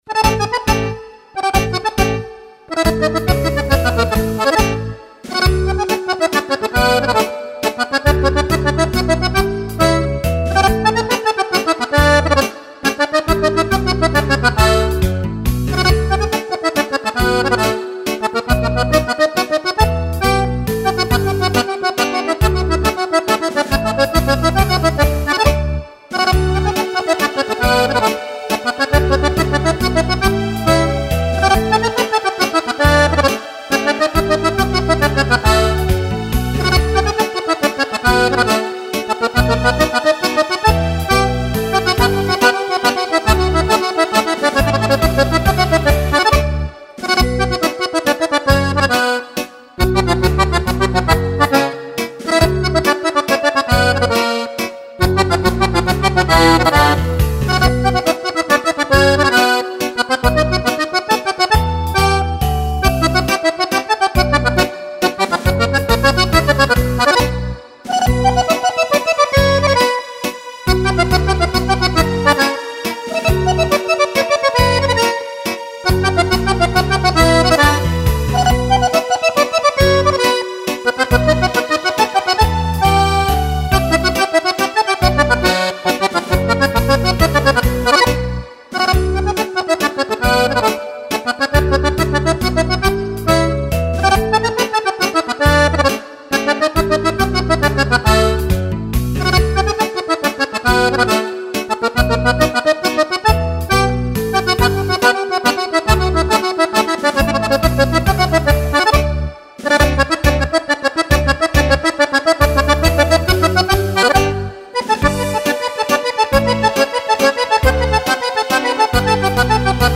Mazurka
Compilation di brani per fisarmonica